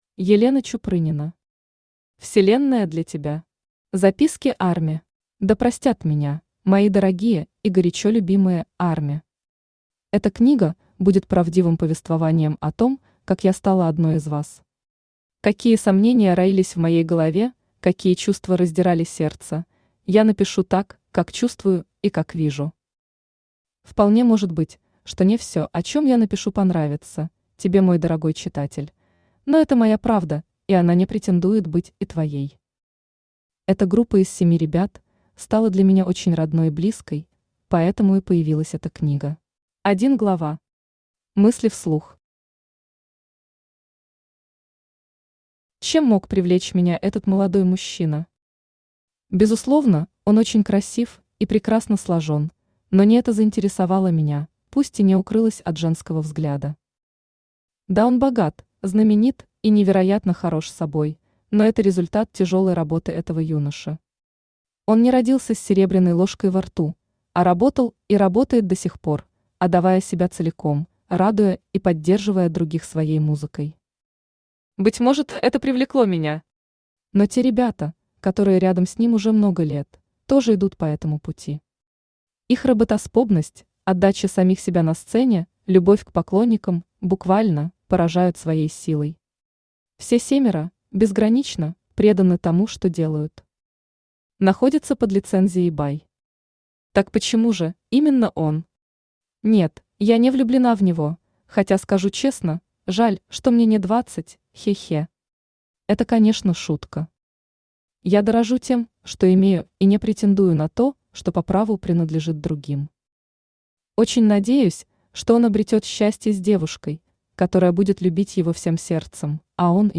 Аудиокнига Вселенная для тебя. Записки ARMY | Библиотека аудиокниг
Записки ARMY Автор Елена Чупрынина Читает аудиокнигу Авточтец ЛитРес.